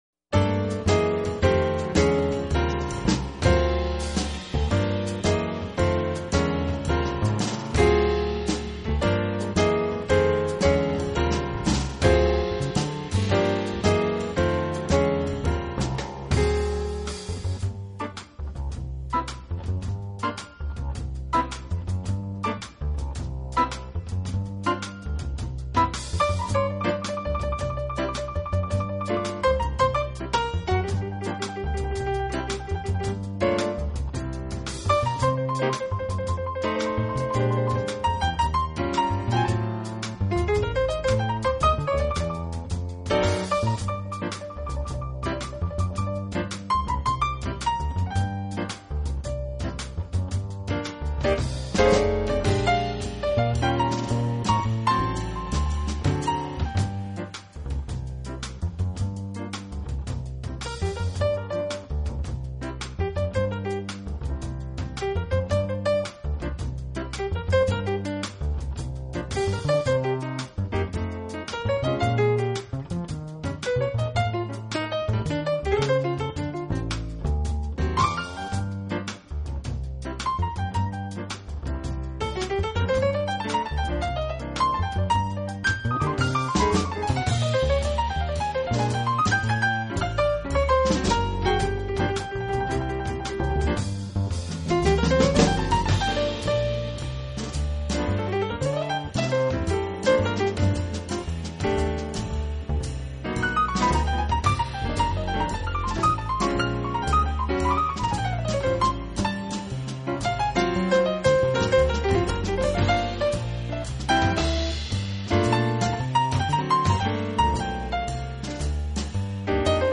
用心复杂难免中庸，好在效果不坏， 乐句是创新的但抒情旋律仍在。